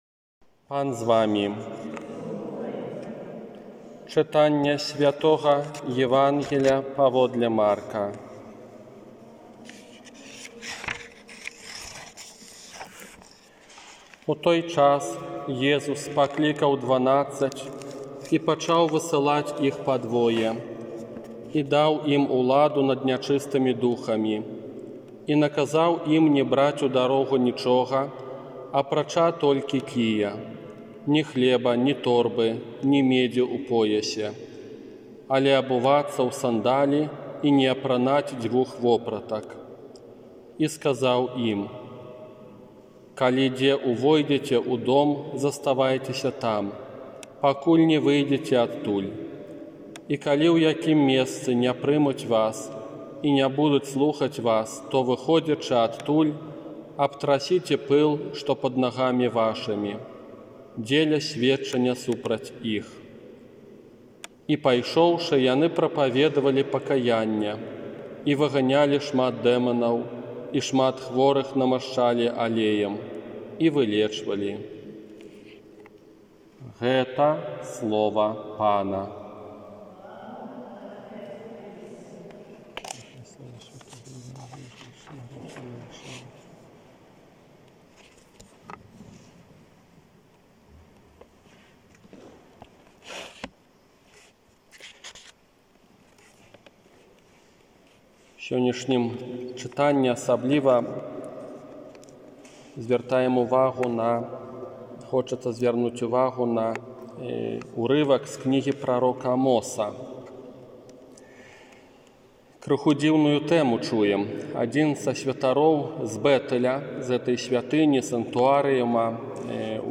ОРША - ПАРАФІЯ СВЯТОГА ЯЗЭПА
Казанне на пятнаццатую звычайную нядзелю 11 ліпеня 2021 года